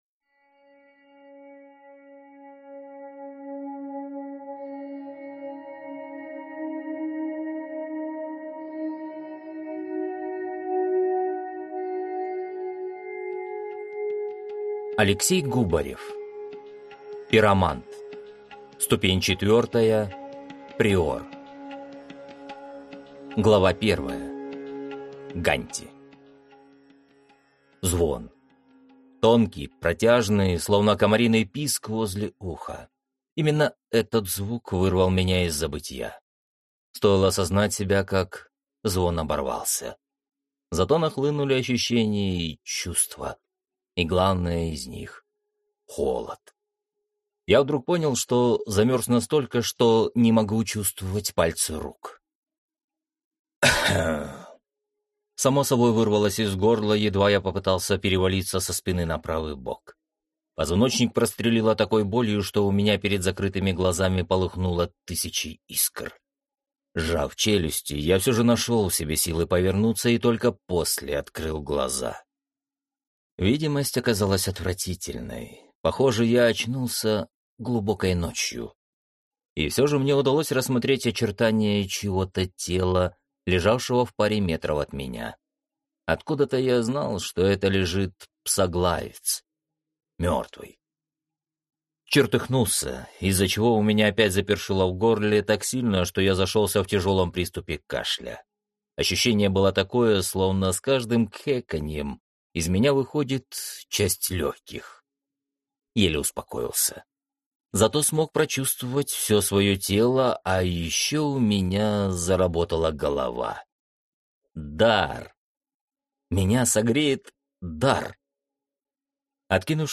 Аудиокнига Пиромант. Ступень 4. Преор | Библиотека аудиокниг